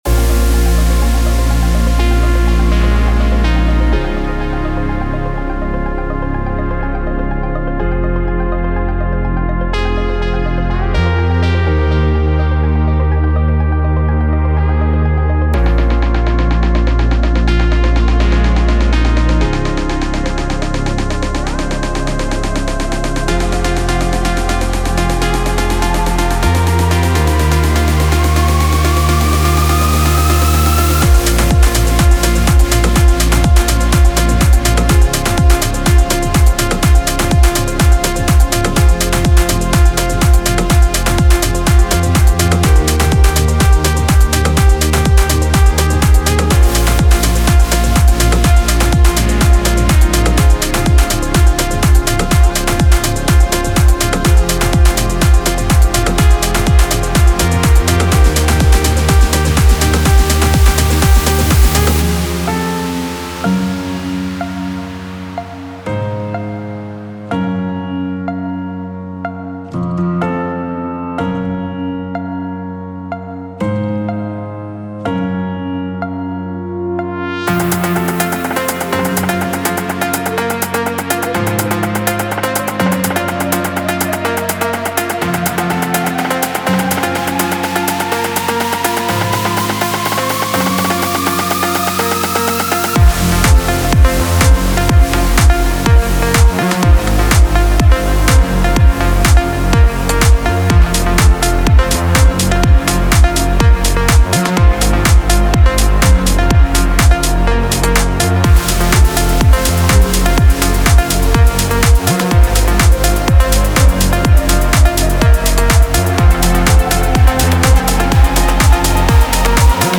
Genre:Progressive House
メインステージでの支配力と深く感情的なクラブ体験の両方に最適です。
デモサウンドはコチラ↓